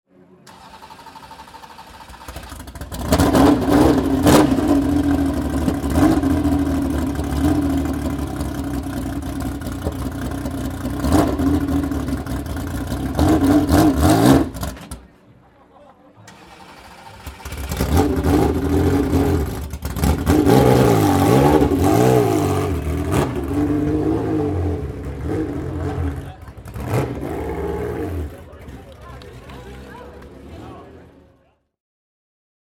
Dino 166P/206P (1965) - Starten des Motors